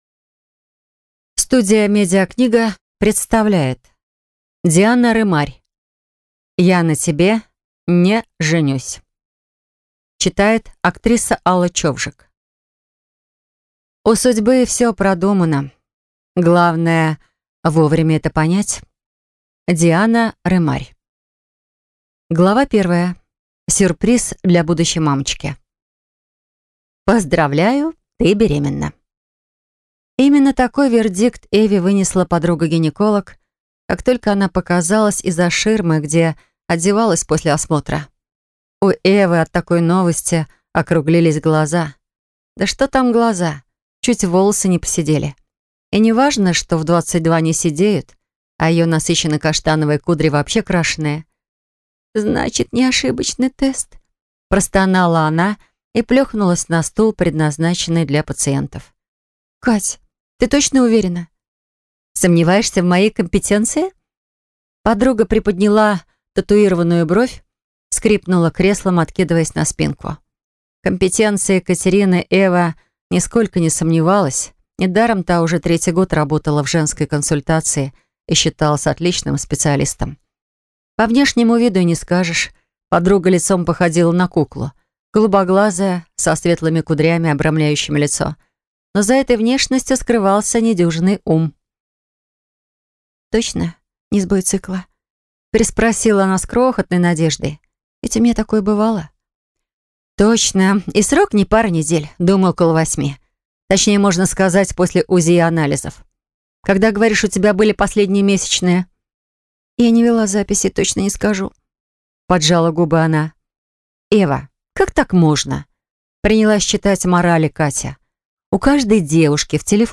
Аудиокнига Я на тебе (не) женюсь | Библиотека аудиокниг